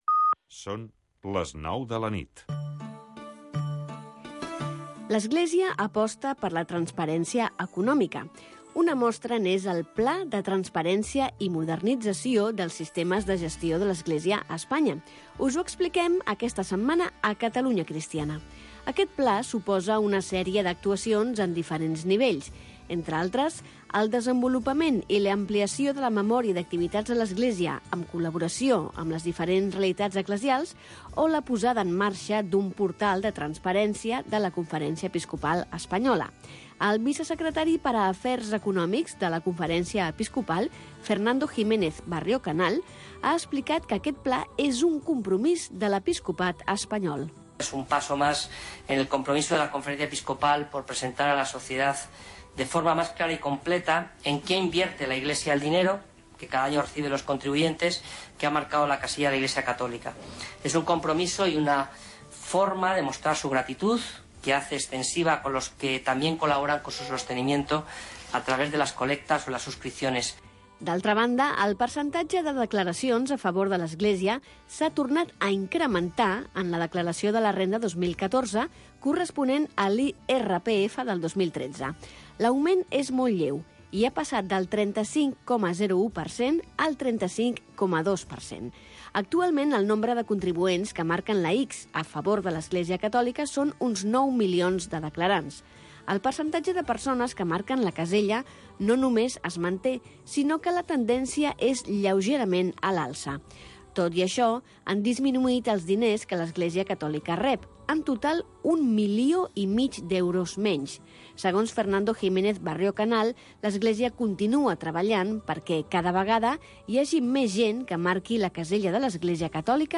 El club del country. Programa de música country. Durant 60 minuts escoltaràs els èxits del moment i els grans clàssics de la música country.